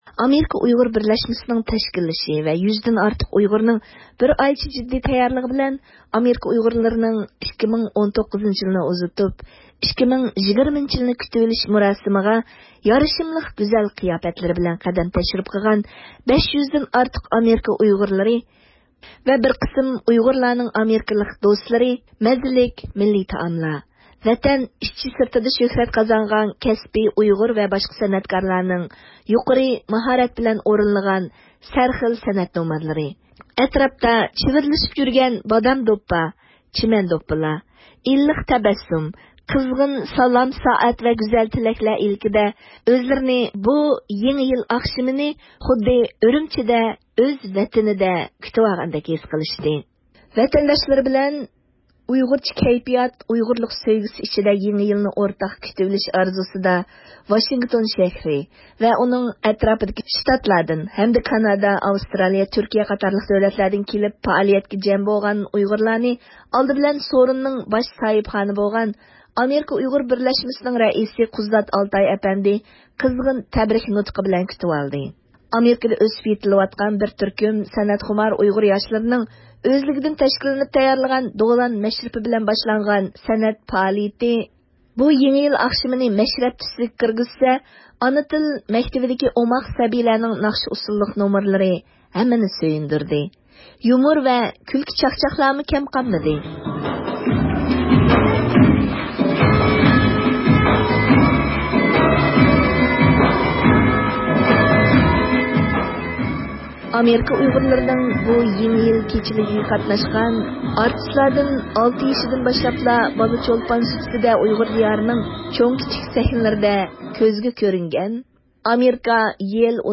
ئۇنىڭغا مىكرافونىمىزنى تەڭلىگىنىمىزدە، ئۇ ئۆزىنىڭ بۇ يېڭى يىلنى ئۇيغۇرلار بىلەن ئۆتكۈزگەنلىكىدىن بەكمۇ خۇشاللانغانلىقىنى بىلدۈردى.